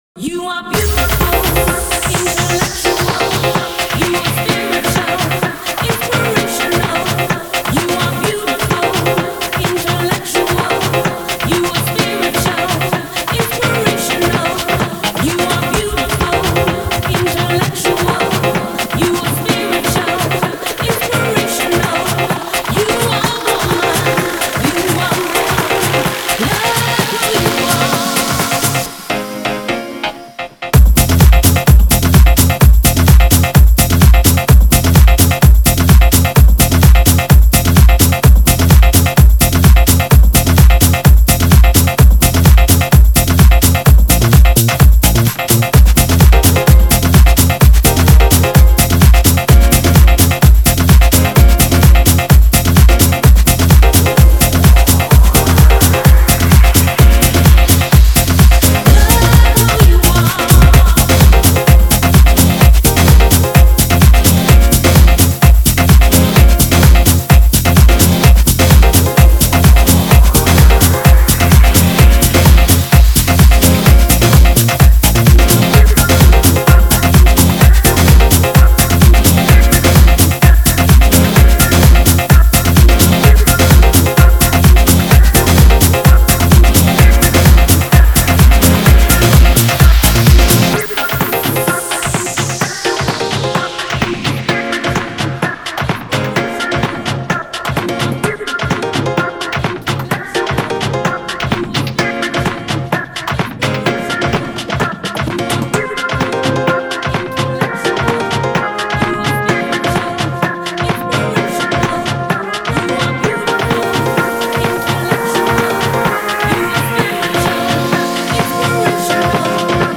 Genre: EDM, House